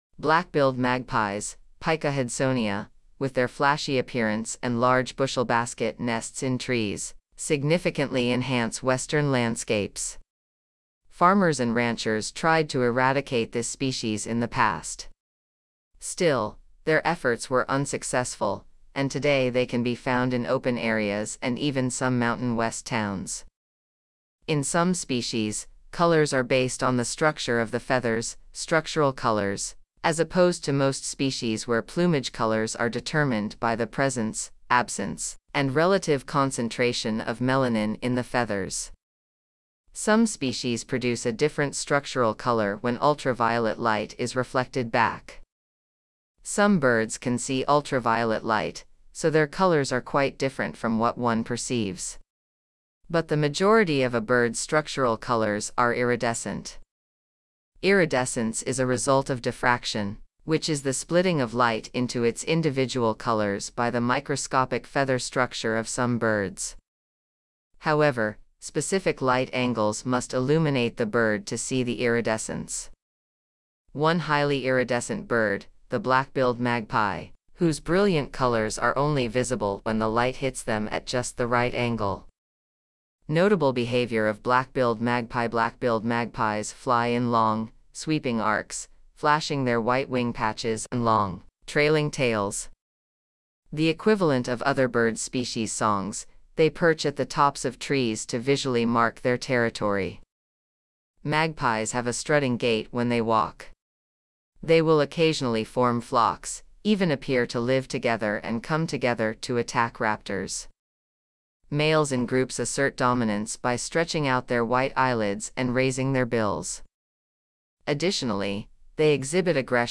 Black-billed Magpie
Black-billed-Magpie.mp3